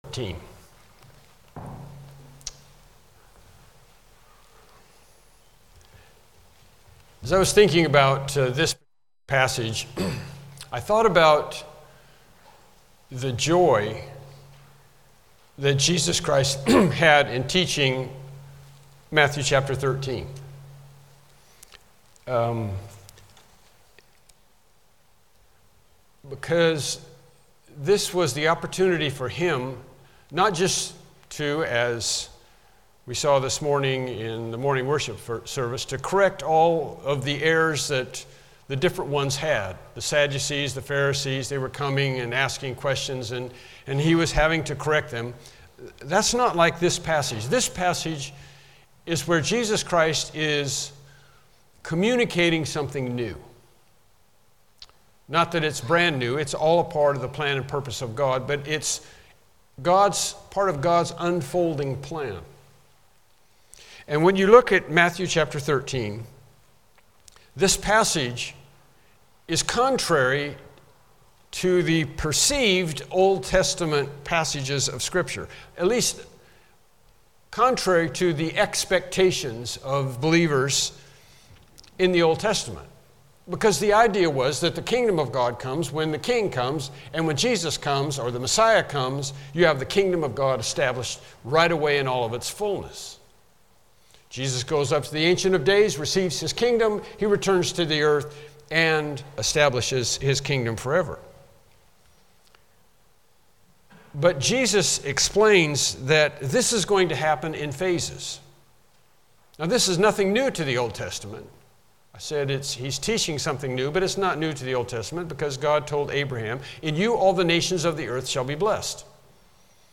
The Study of Things to Come Service Type: Evening Worship Service « The Resurrection Question Lesson 10